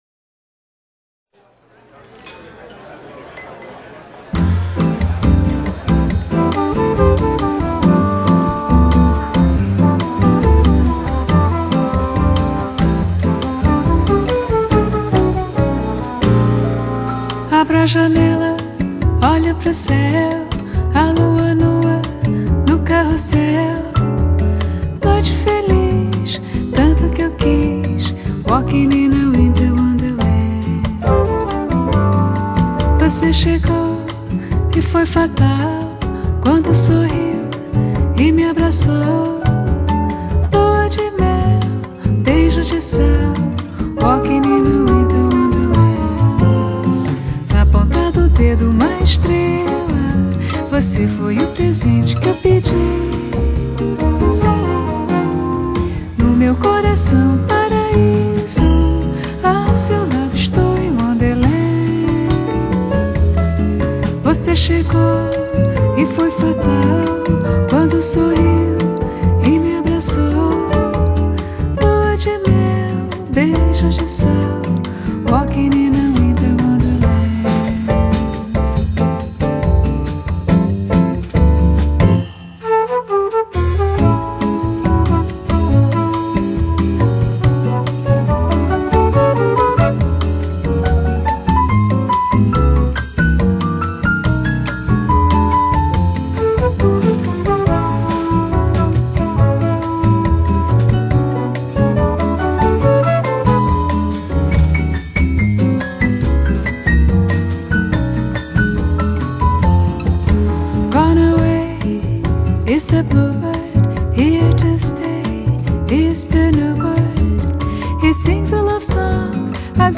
Genre: Latin Jazz, Bossa Nova